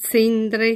Cortina ~ Cortina al contrario… solo in quest’ultima si sente chiaramente uno [st̚] alla fine.
A ogni modo, non mi sembra che quella di Cortina sia una sequenza.